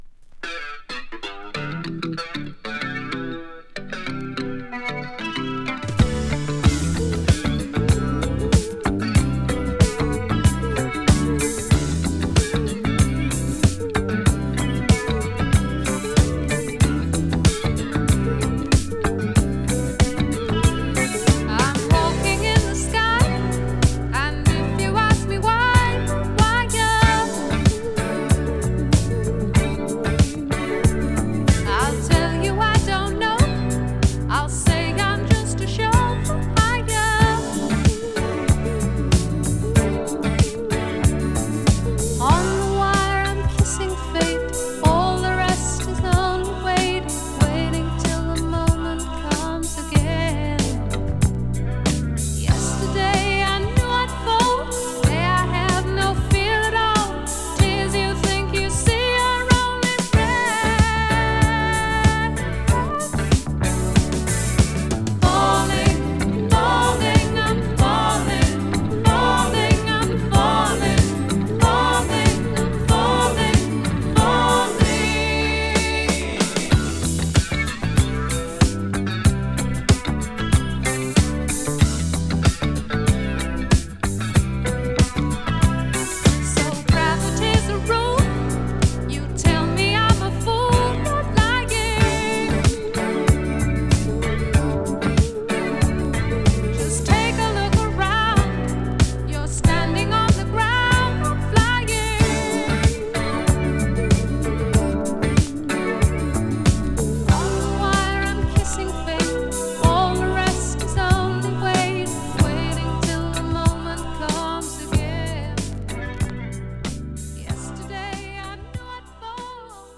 2. > ROCK/POP